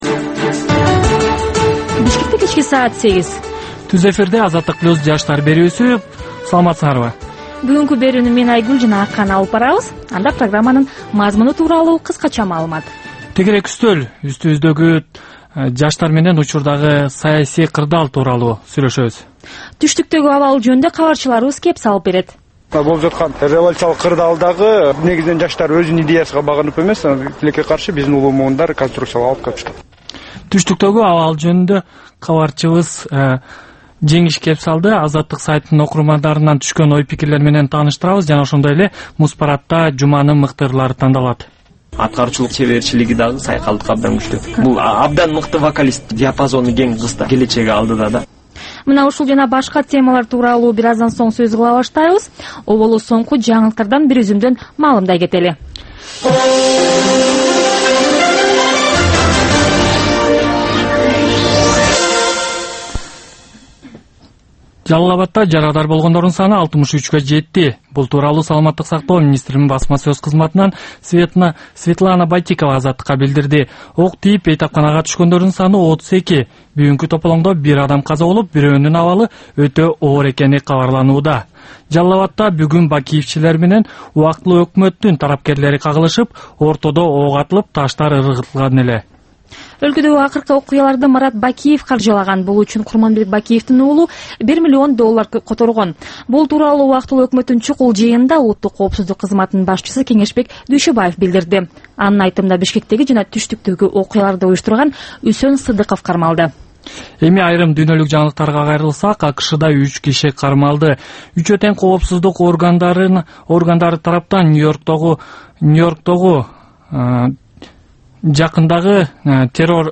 Бул жаштарга арналган кечки үналгы берүү жергиликтүү жана эл аралык кабарлардын чакан топтому, ар кыл репортаж, сереп, маек, маданий, спорттук, социалдык баян, тегерек үстөл четиндеги баарлашуу жана башка кыргызстандык жаштардын көйгөйү чагылдырылган берүүлөрдөн турат. "Азаттык үналгысынын" бул жаштар берүүсү Бишкек убакыты боюнча саат 20:00дан 20:30га чейин обого түз чыгат.